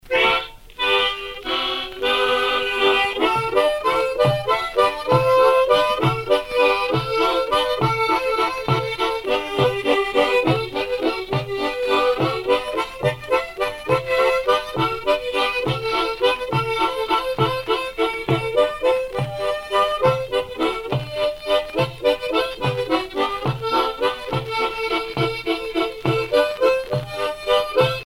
Valse
danse : valse